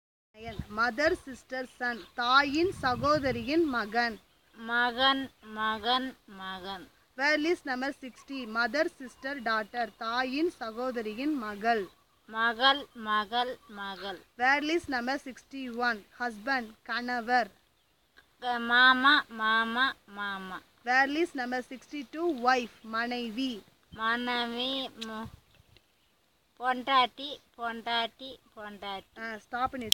NotesThis is an elicitation of words for kinship terms, using the SPPEL Language Documentation Handbook.